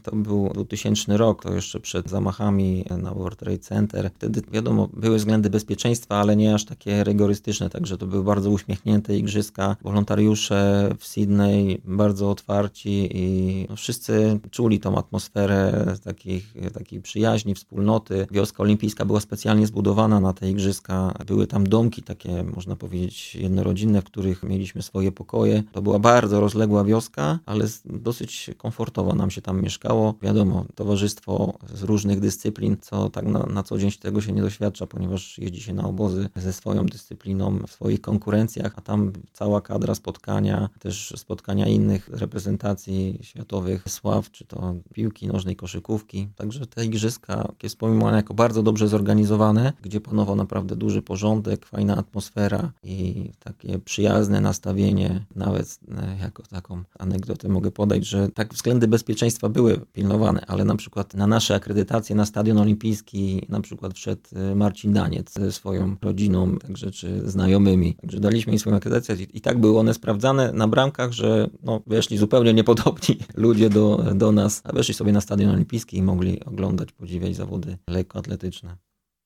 Na naszej antenie wspomina igrzyska w Sydney i opowiada anegdotę z… Marcinem Dańcem w roli głównej.